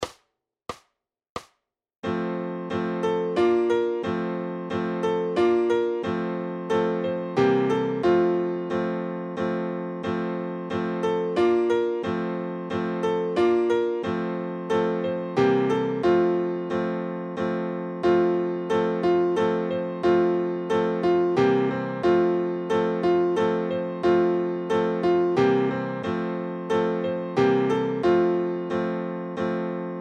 Noty na snadný klavír.
Aranžmá Noty na snadný klavír
Hudební žánr Vánoční písně, koledy